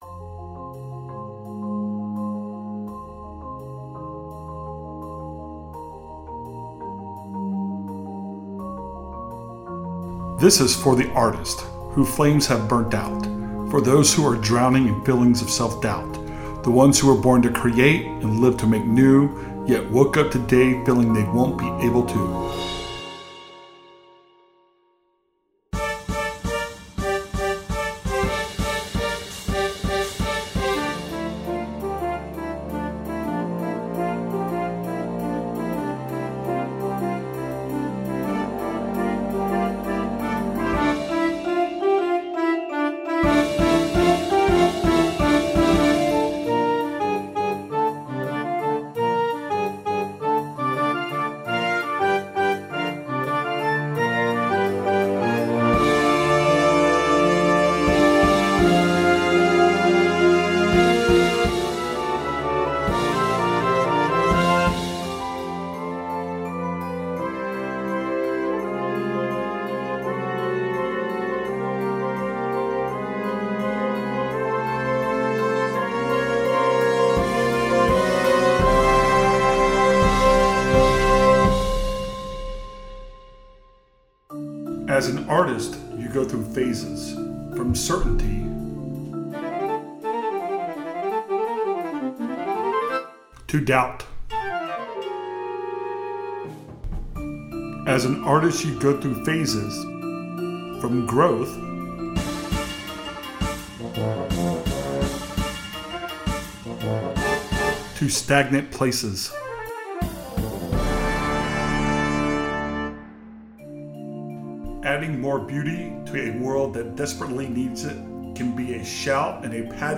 Vocal choir can be incorporated into the program.